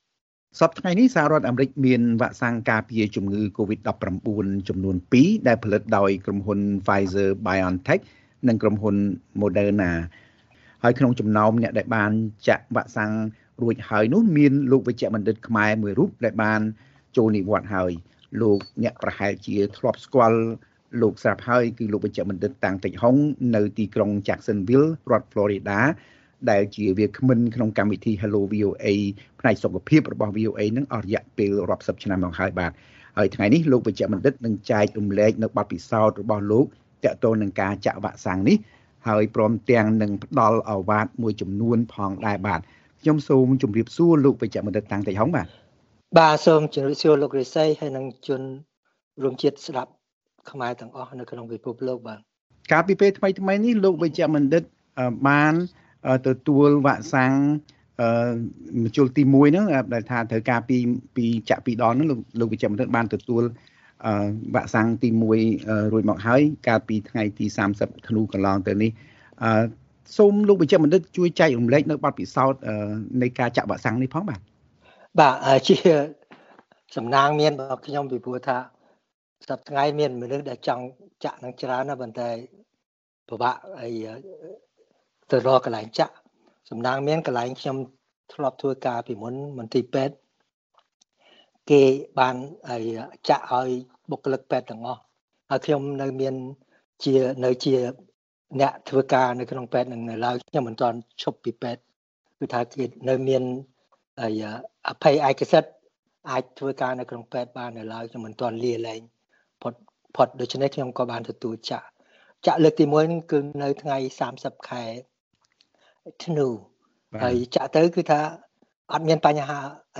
បទសម្ភាសន៍ VOA៖ ការពាក់ម៉ាស់និងការរក្សាគម្លាតពីគ្នាត្រូវបន្ត ក្រោយការចាក់វ៉ាក់សាំងកូវីដ១៩